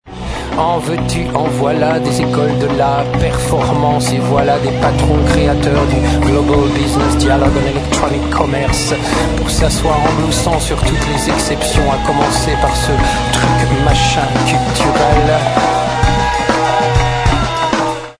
gardon, tabourin, trançonneuse, cruche